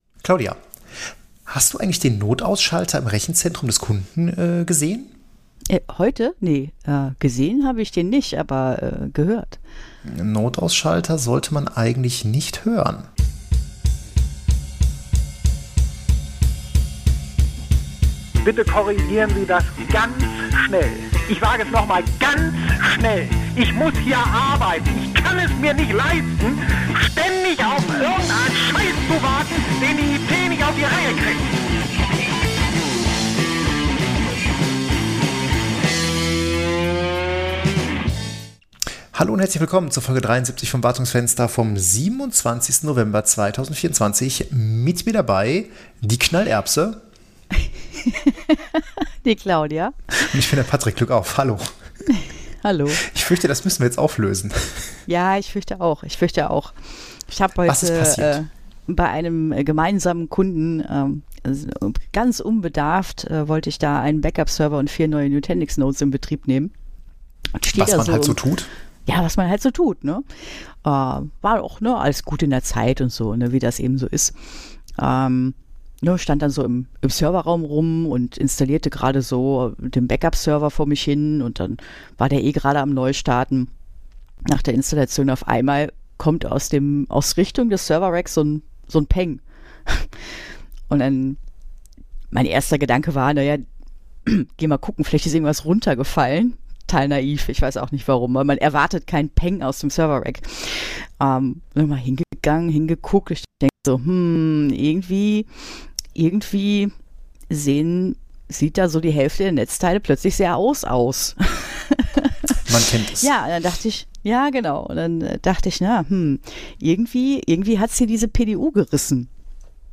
Es gibt nicht nur neue Sicherheitslücken, sondern auch einen neuen Player, der die Hypervisor-Arena betreten hat. Viel Spaß bei unserer ersten Hotel-Aufnahme!